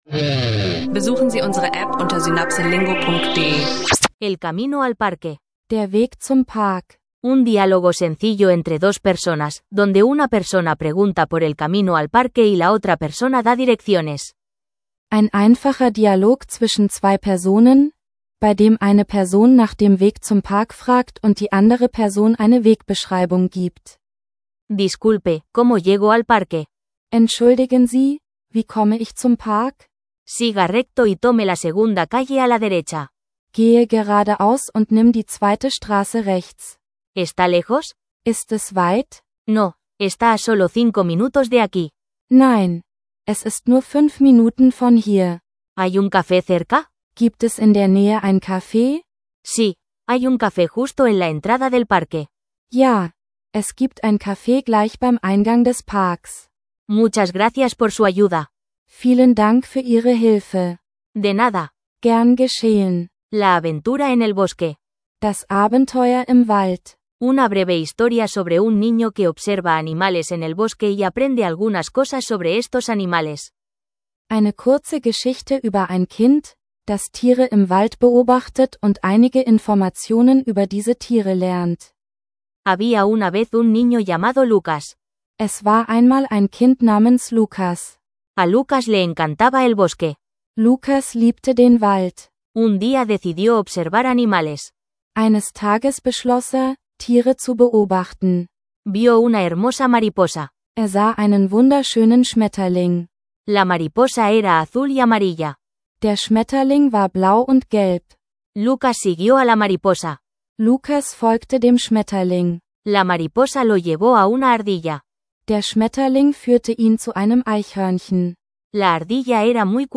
Mit SynapseLingo spielend Spanisch lernen! Diese Episode bietet dir einen einfachen Dialog über den Weg zum Park und hilft Anfängern dabei, wichtige Vokabeln und Phrasen für Alltagsgespräche zu entdecken.